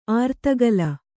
♪ ārtagala